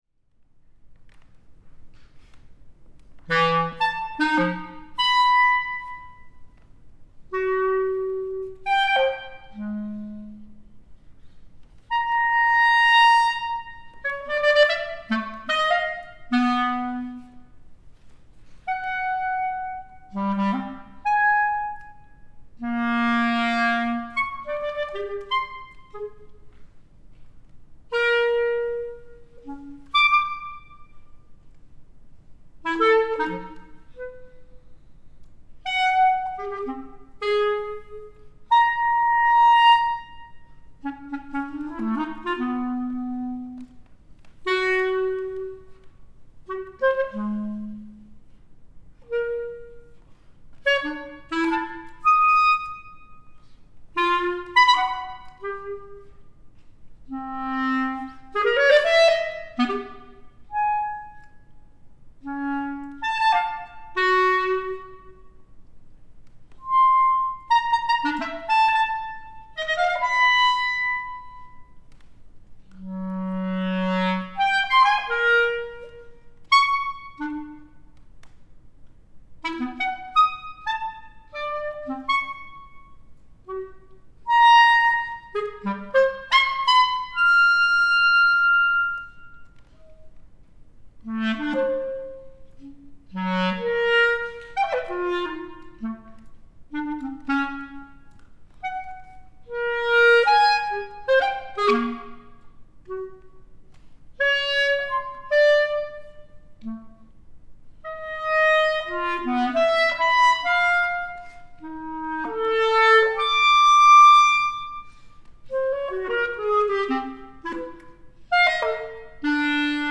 Classical Works
MoodTwoforClarinet.mp3